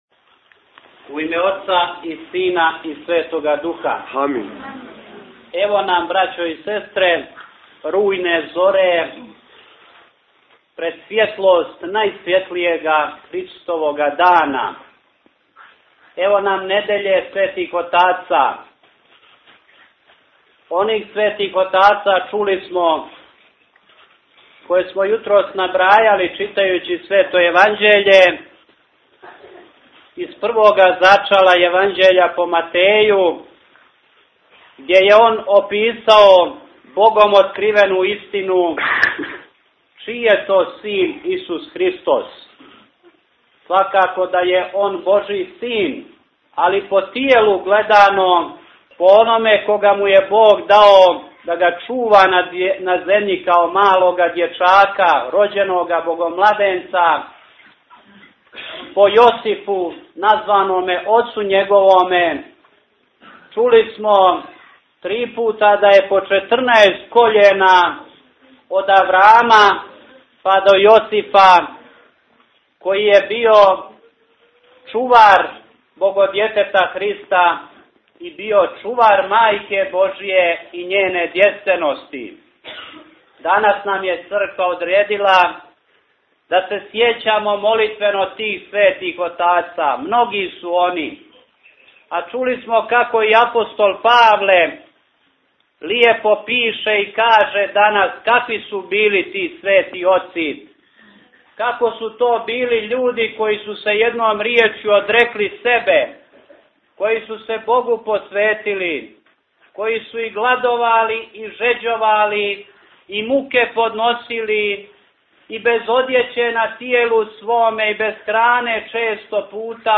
Tagged: Бесједе